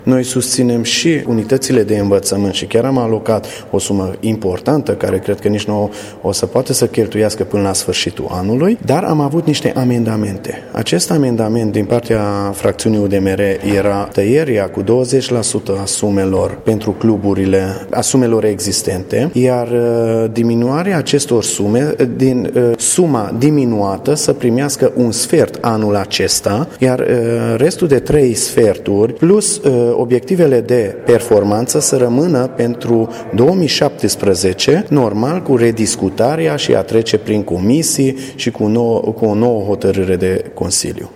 Consilierul local Csiki Zsolt: